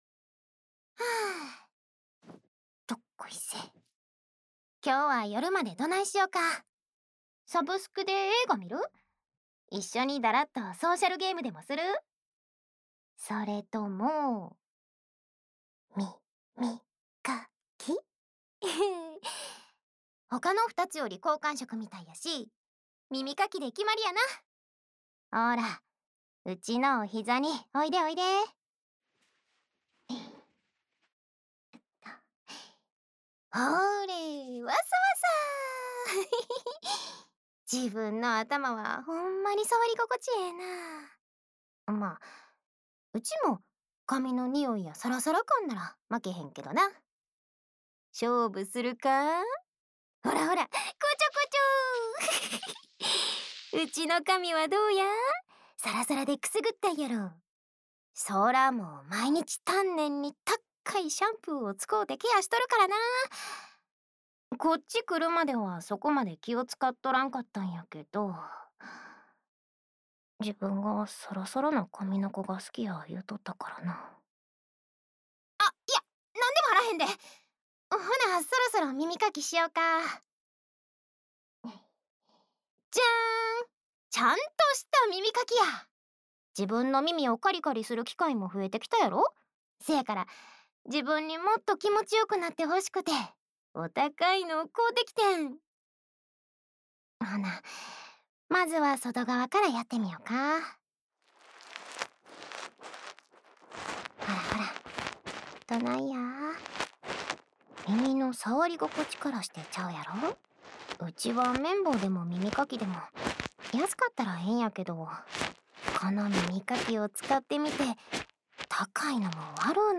日常/生活 治愈 关西腔 掏耳 环绕音 ASMR 低语
el90_02_『就决定是掏耳了。过来躺在我腿上吧（掏耳左）』.mp3